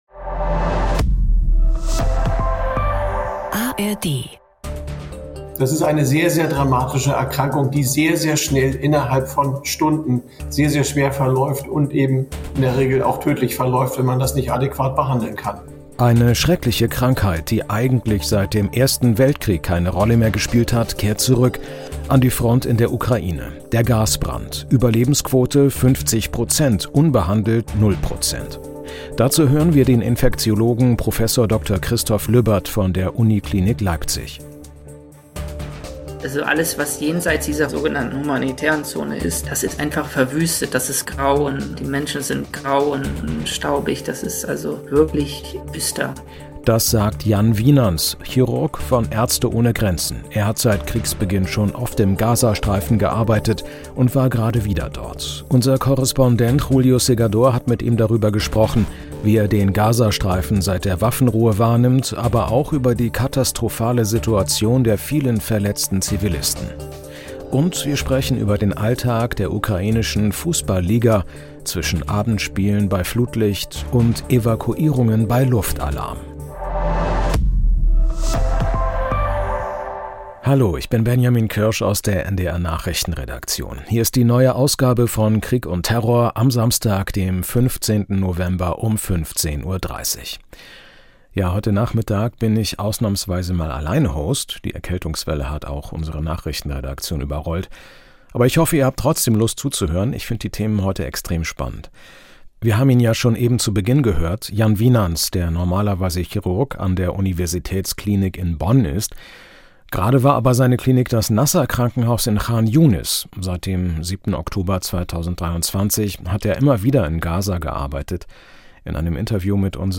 Im Ukraine-Krieg taucht gerade die vergessene Krankheit Gasbrand wieder auf. Ein Experte erklärt die Ursachen und warum die Behandlung an der Front so schwierig ist.│ Ein Chirurg von Ärzte ohne Grenzen schildert die Lage im Gazastreifen nach der Waffenruhe und die Versorgung der Verletzten.